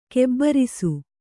♪ kebbarisu